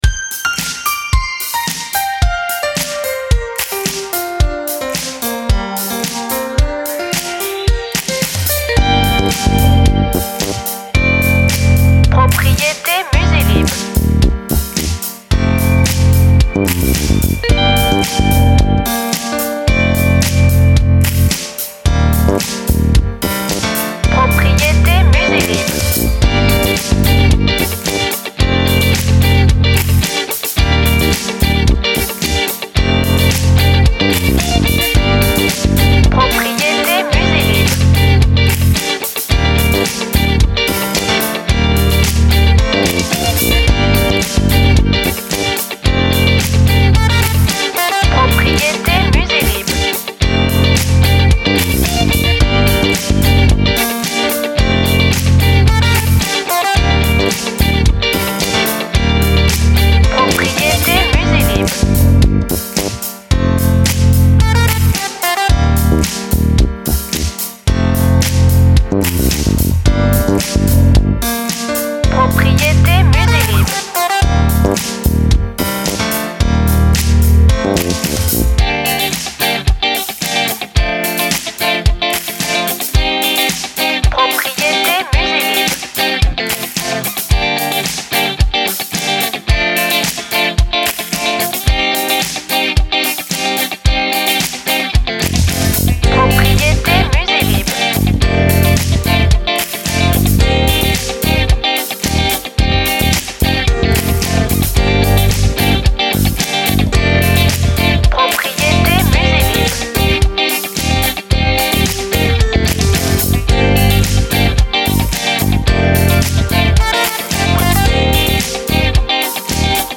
BPM Moyen